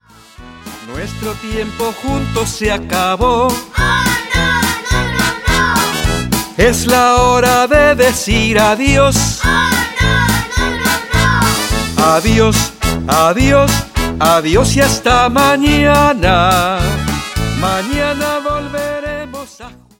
fun Spanish children’s song